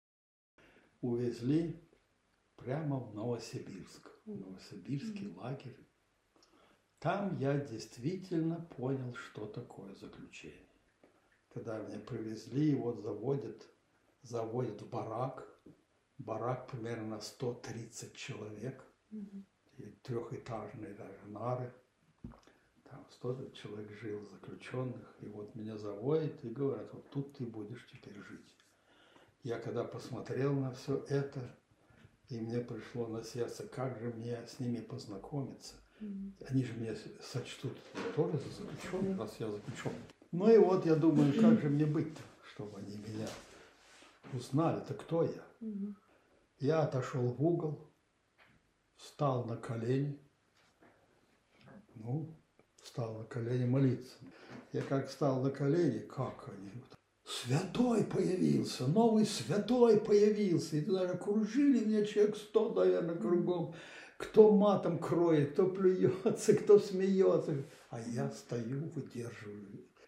Interviewsequenz Haft